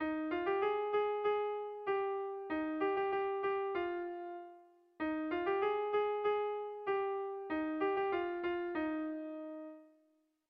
Air de bertsos - Voir fiche   Pour savoir plus sur cette section
Tragikoa
A1A2